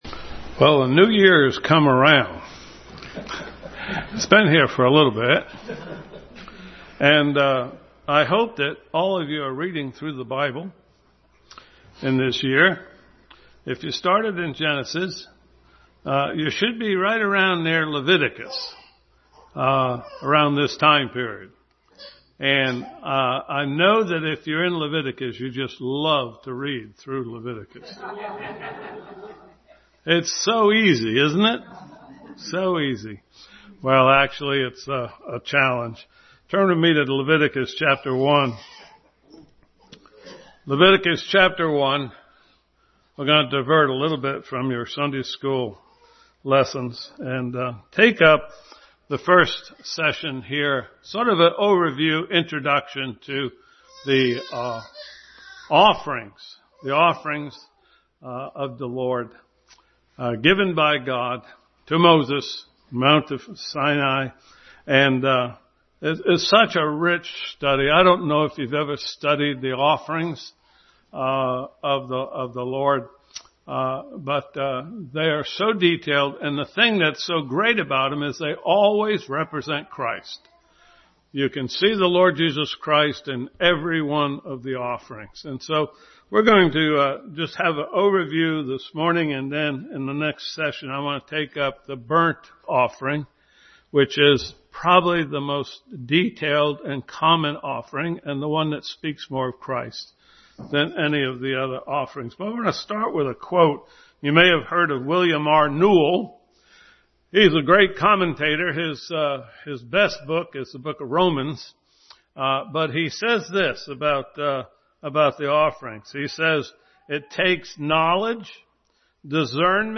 The Offerings Passage: Leviticus, Micah 6:3-8, Philippians 4, Psalm 24:3-4, 1 John 1:9 Service Type: Sunday School